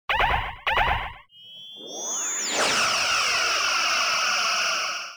phaser.wav